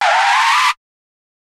Roland.Juno.D _ Limited Edition _ GM2 SFX Kit _ 03.wav